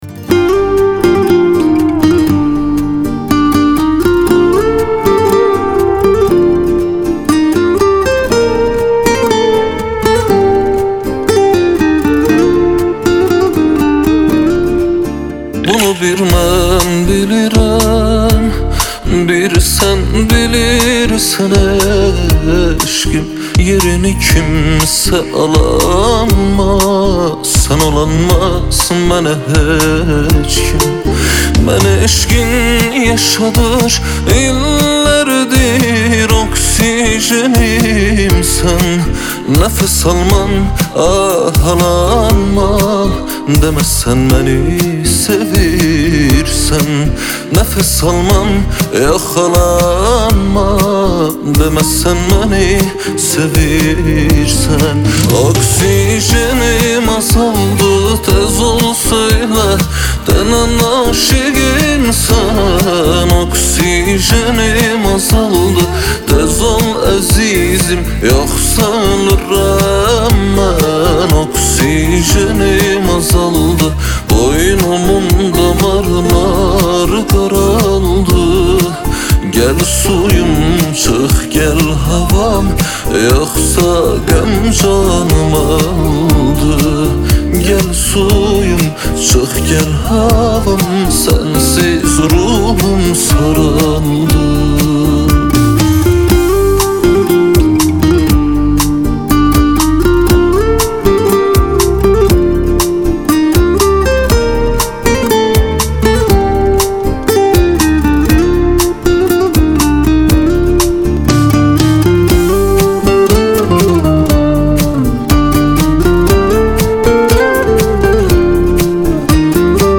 آهنگ آذربایجانی آهنگ غمگین آذربایجانی آهنگ هیت آذربایجانی